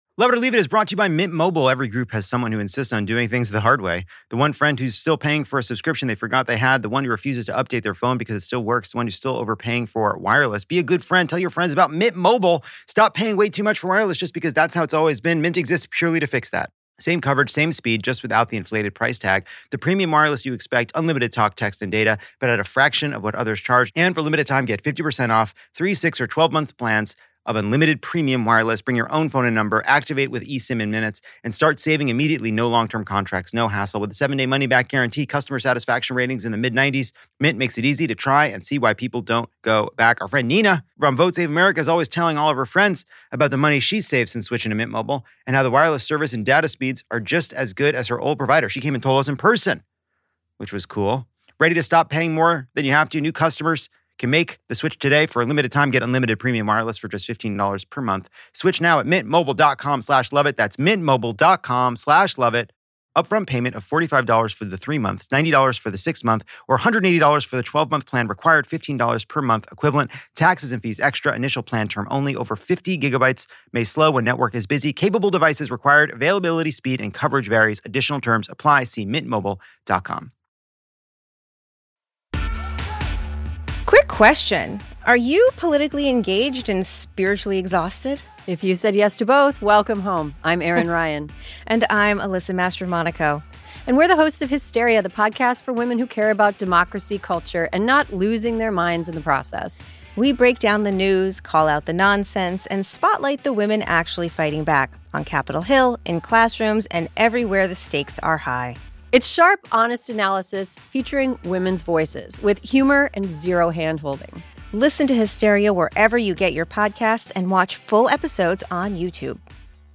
Trump says we should smile more and talk less about the Epstein files, which is not going so well for him. In a barnburner of an episode, Lisa Rinna takes the Lovett or Leave It stage to take us behind the scenes of Traitors - and explain what happens when the inner Housewife is released. Then Rachel Bloom is back and we fall in love all over again.